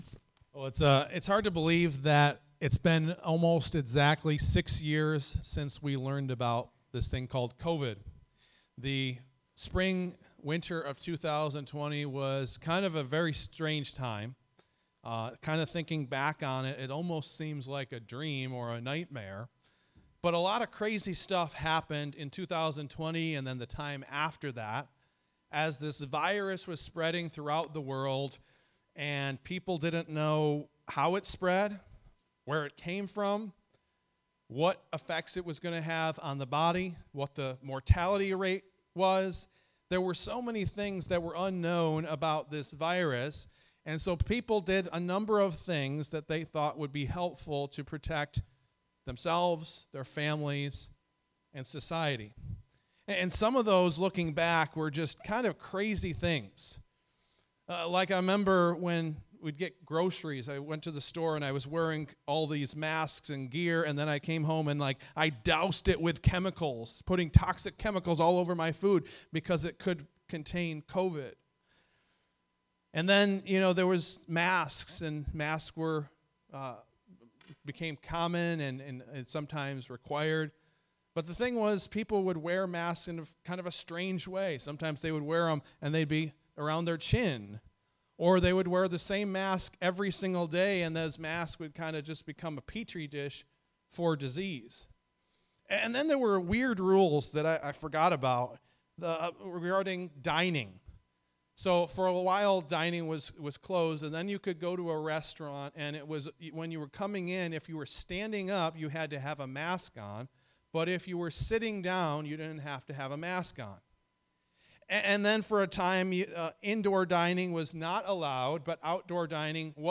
Message 1.18.26 Idol of Control.m4a